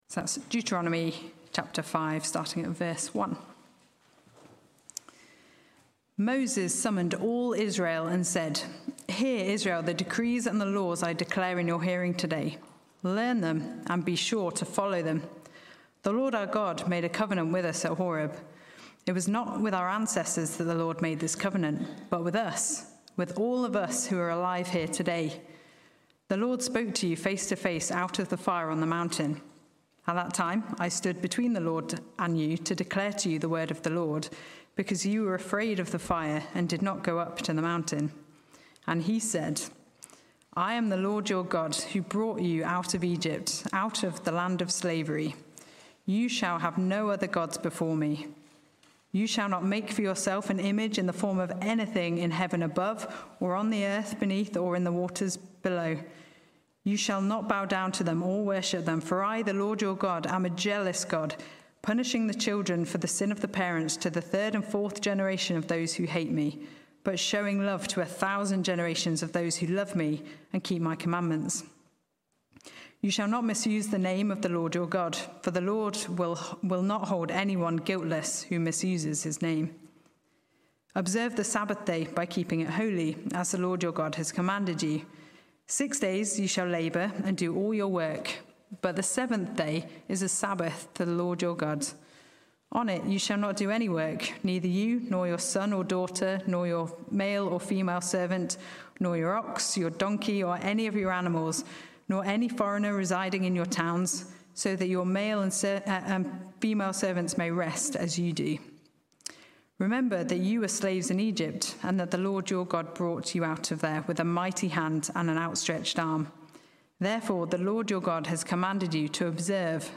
Sermons Archive - Page 17 of 187 - All Saints Preston